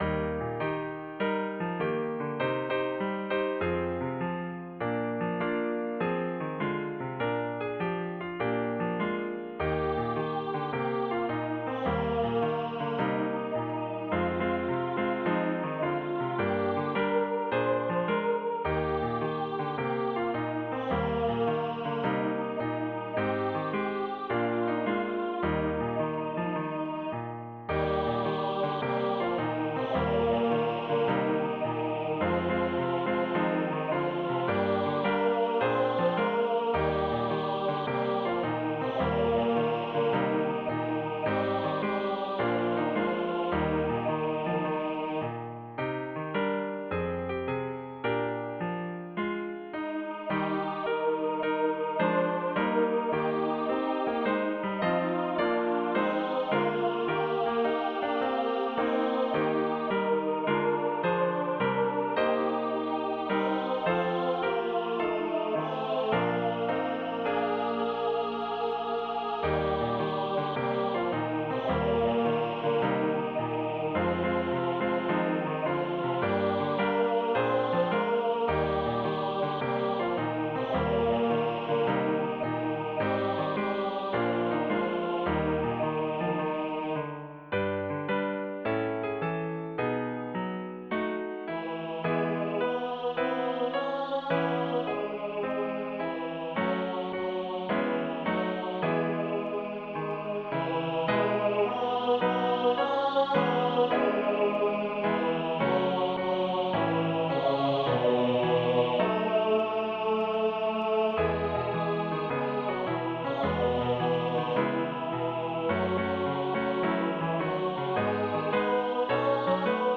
SATB
My choir director requested that I write an energetic SATB choir arrangement of hymn #1208 Go Tell It on the Mountain.
The voice parts come straignt from the hymn arrangement except for the harmonies in the last refrain.
A 2 page voice parts score is also included as well as a computer generated sound file.